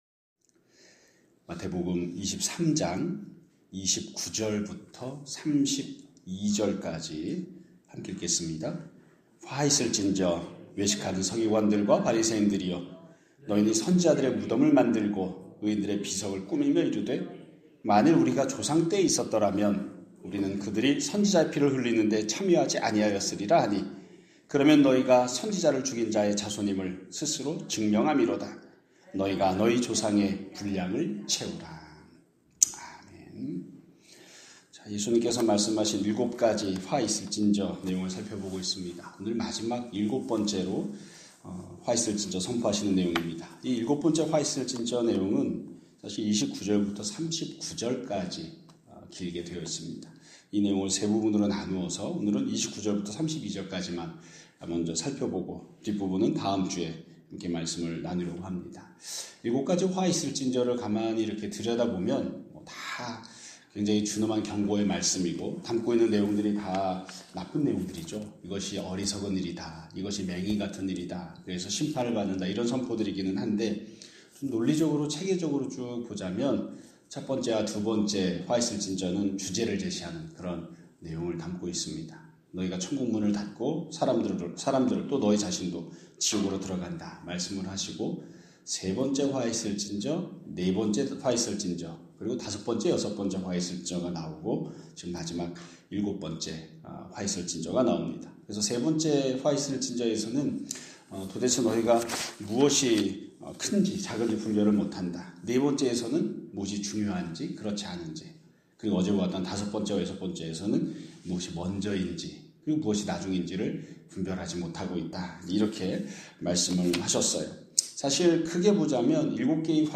2026년 2월 27일 (금요일) <아침예배> 설교입니다.